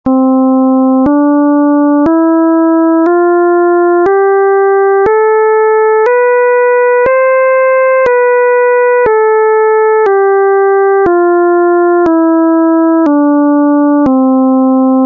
Βυζαντινή Μουσική - Κλίμακες
Διατονικὸ σύντονο
Οἱ ἤχοι ἔχουν παραχθεῖ μὲ ὑπολογιστὴ μὲ ὑπέρθεση ἀρμονικῶν.
diatonic_suntono_262.mp3